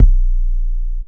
Waka KICK Edited (68).wav